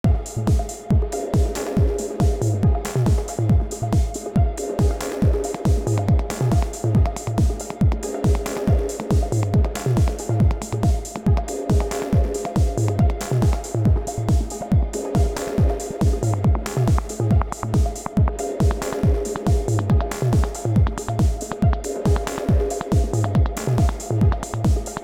To be honest, sometimes it’s worse, but here’s a bunch of snippets I just recorded, taken from my last improv practice session.
It starts already a bit busy but a bit restrained at least: